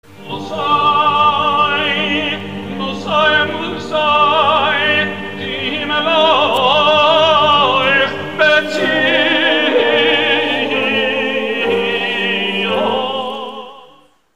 Правильный ответ: Иудаизм (на аудио пение кантора)